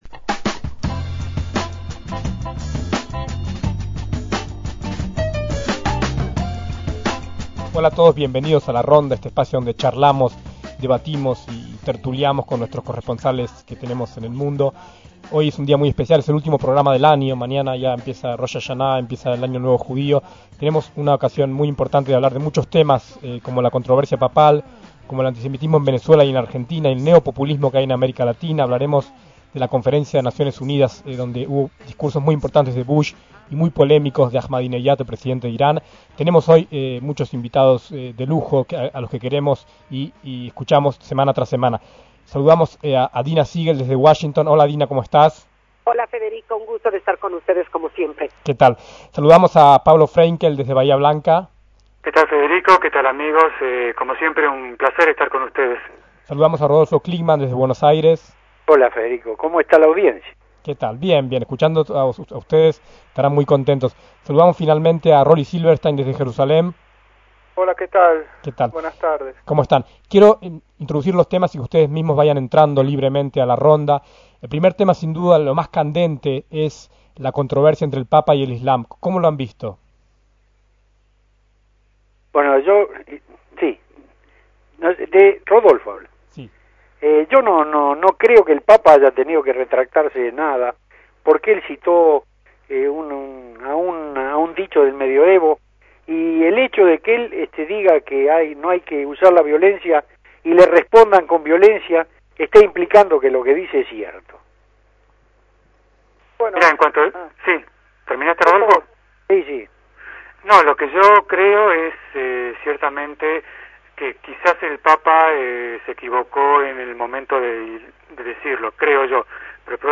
la ronda de corresponsales de Radio Sefarad por el mundo